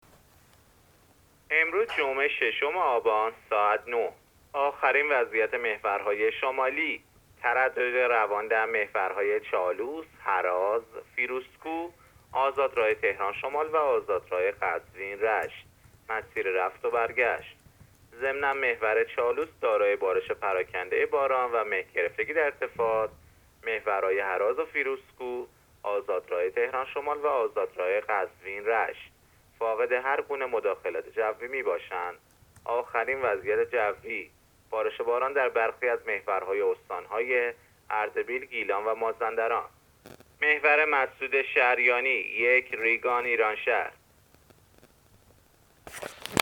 گزارش رادیو اینترنتی از آخرین وضعیت ترافیکی جاده‌ها تا ساعت ۹ ششم آبان؛